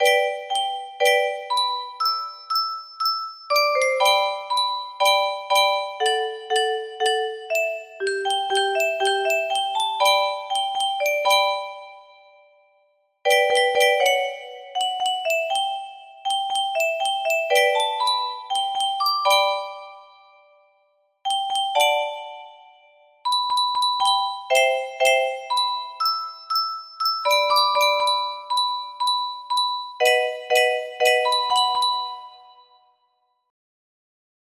Grand Illusions 30 (F scale)
BPM 60